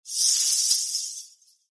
Danza árabe, ruido de monedas de un pañuelo de una bailarina 02
continuo
moneda
Sonidos: Acciones humanas